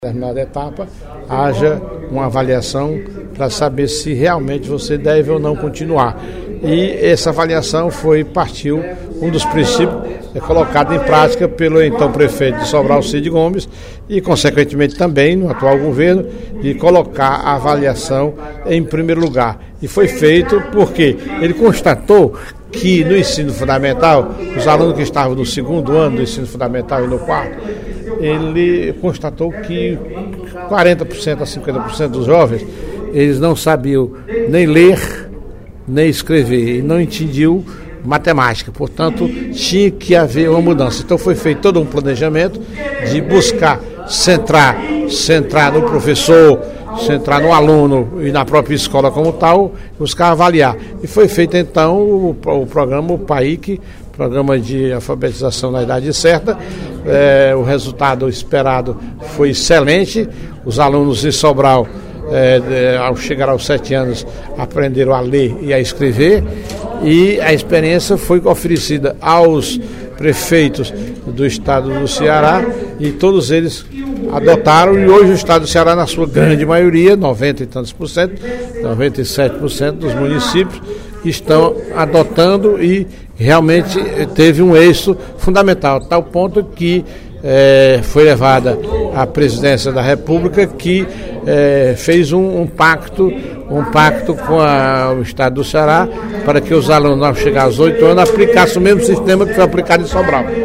O deputado Professor Teodoro (PSD) destacou a importância da avaliação no sistema educacional brasileiro, durante pronunciamento no primeiro expediente da sessão plenária desta sexta-feira (14/06), na Assembleia Legislativa.